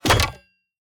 UI_BronzeFall_Wood.ogg